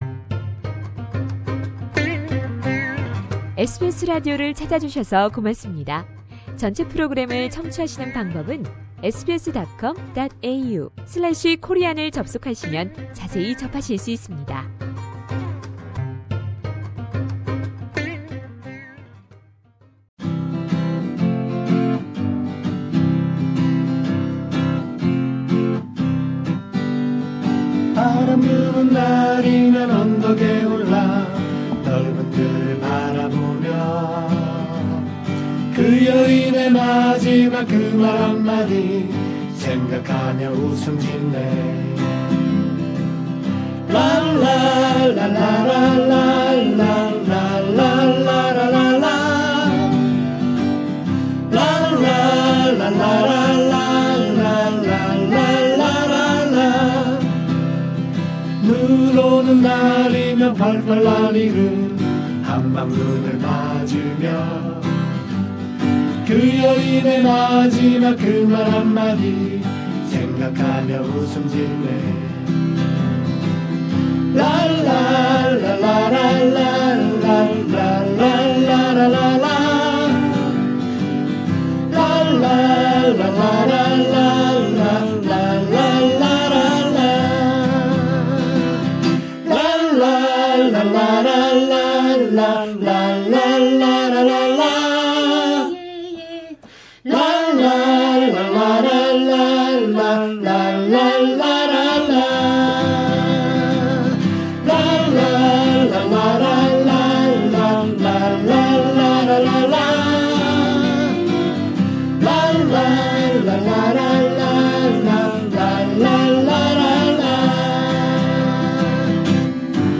4월 29일 가을 어쿠스틱 음악회를 앞두고 있는 호주통사모를 SBS Radio 스튜디오 미니콘서트로 만나본다.
상단의 다시 듣기(Podcast)를 클릭하시면 미니 콘서트를 만날 수 있습니다.